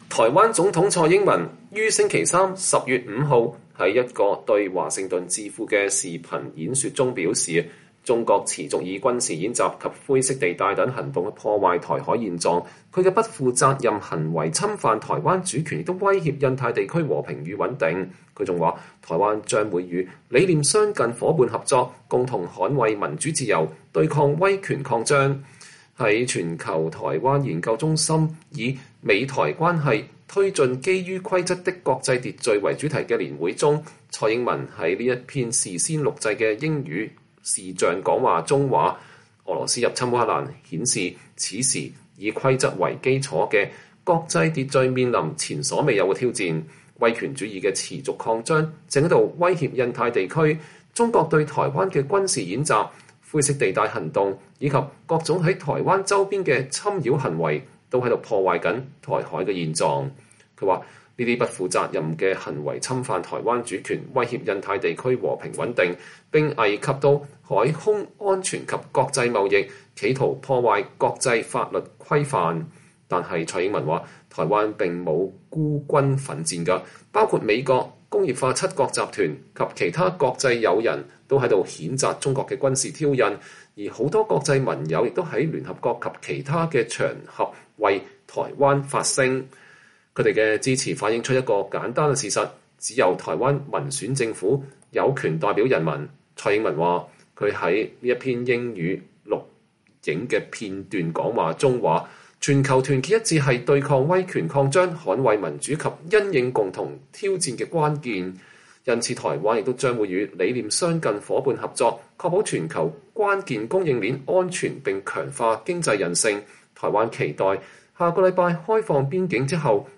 蔡英文對美智庫發表視頻演說：中國破壞台海現狀威脅印太和平穩定